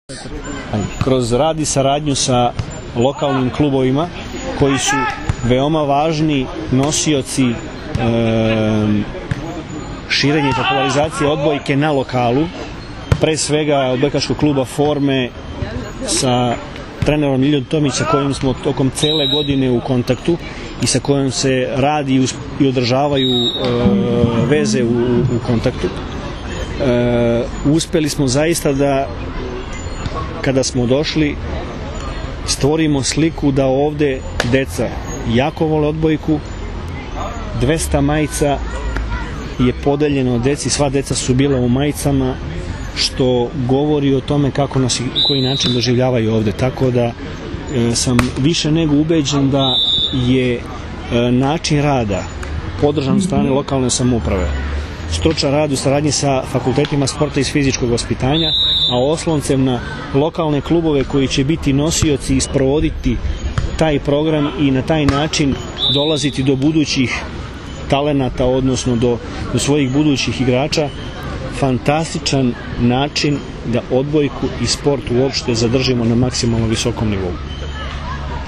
IZJAVA VLADIMIRA GRBIĆA 2